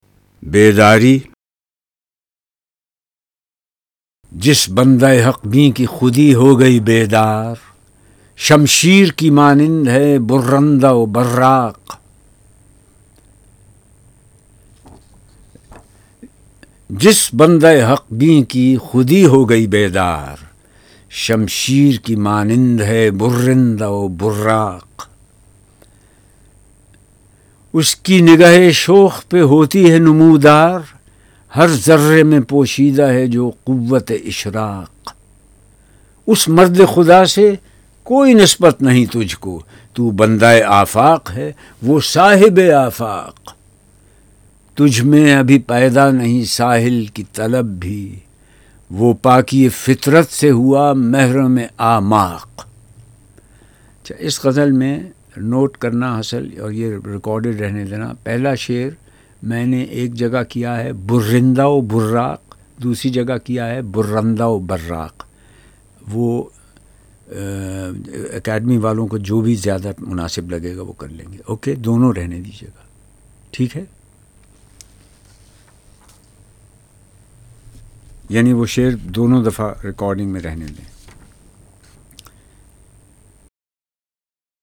Zia Muhauddin Reads Zarb e Kaleem - International Iqbal Society - Allama Iqbal